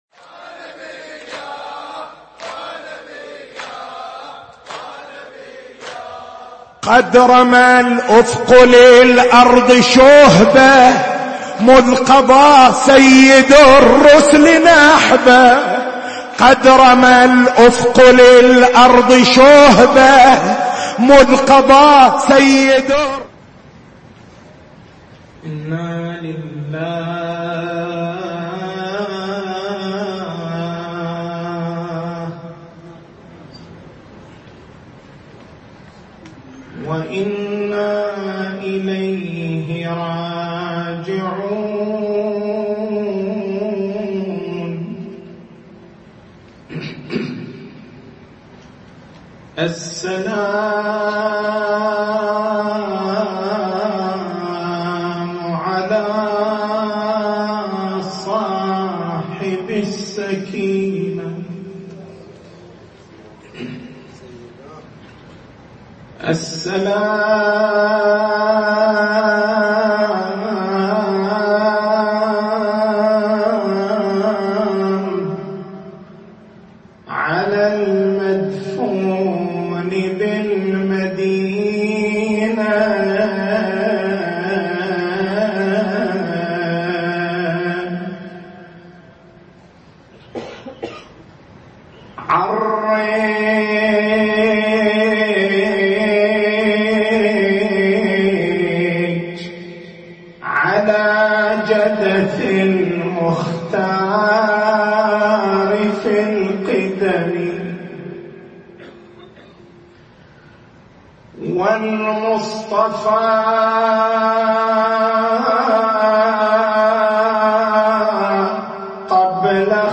تاريخ المحاضرة: 28/02/1439 محور البحث: ما هو المنهج الصحيح في التعرّف على السيرة النبوية المباركة؟